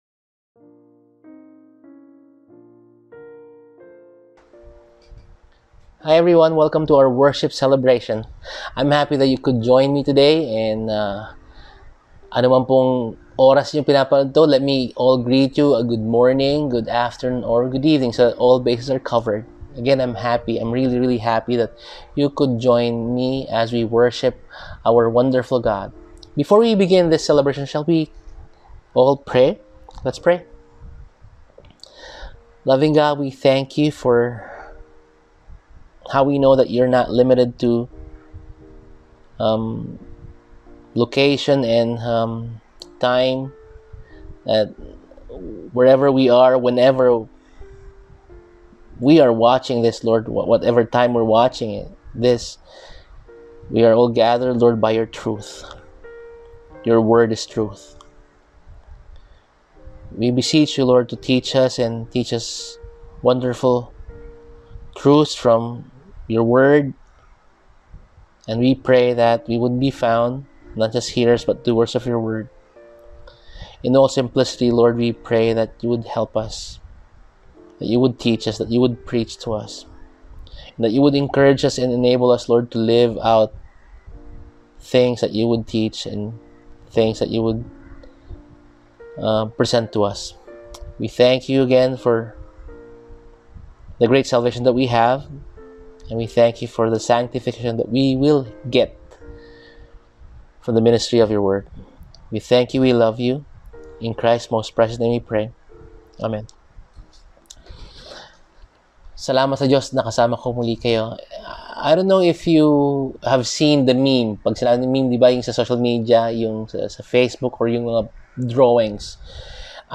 Service: Sunday